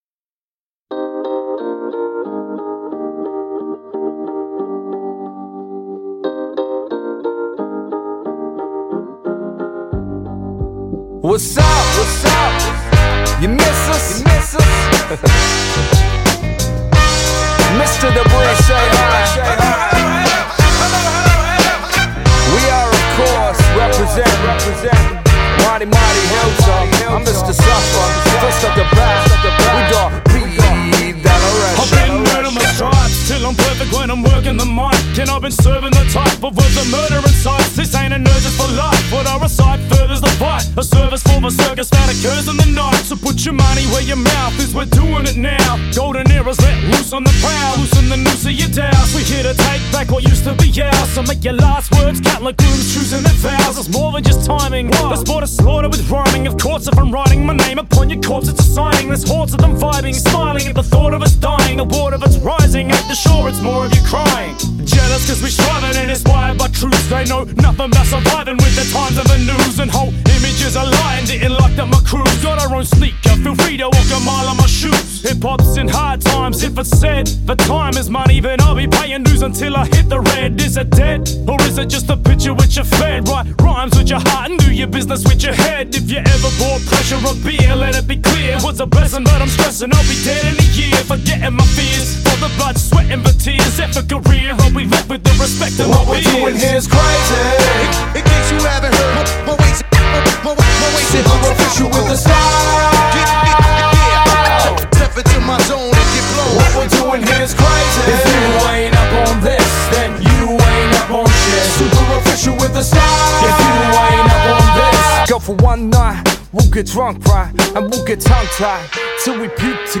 非常独特的说唱音乐风格 曲风有爵士和电子风格 flow平稳